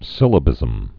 (sĭlə-bĭzəm)